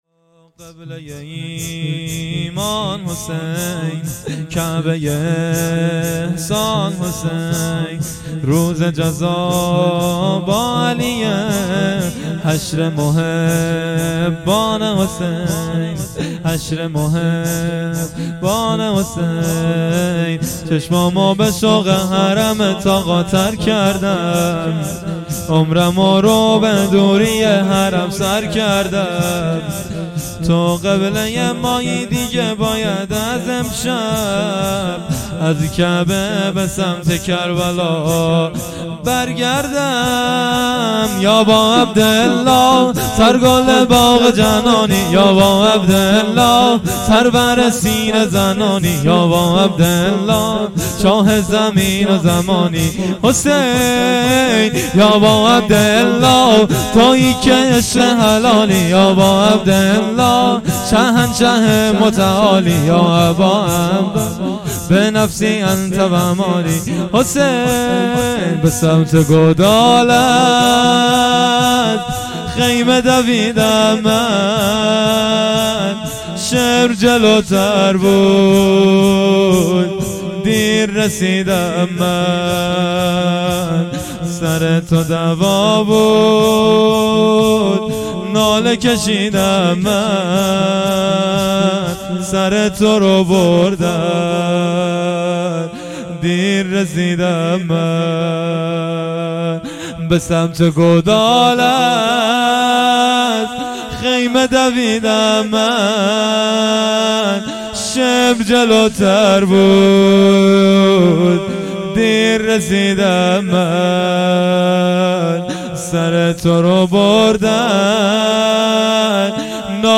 مراسم عزاداری دهه اول محرم الحرام 1399 - گلزار شهدای هرمزآباد